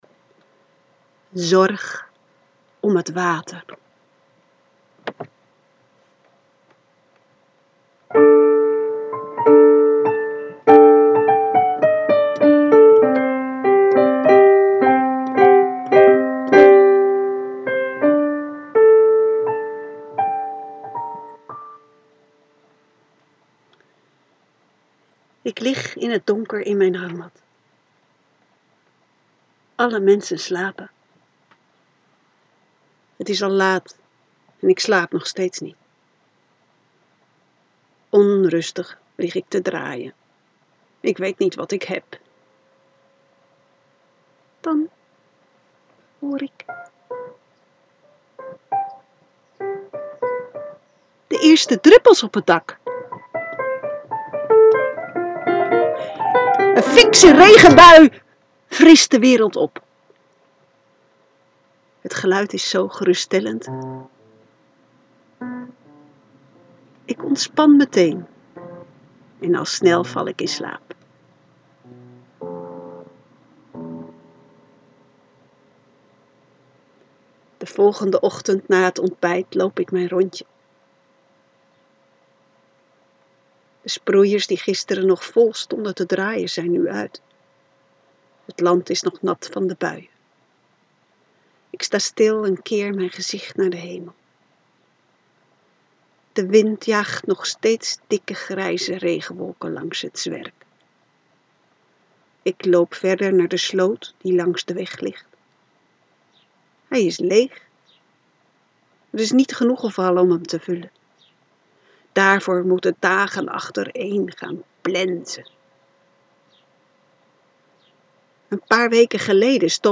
(Sorry voor het slechte geluid in het begin, beetje jammer.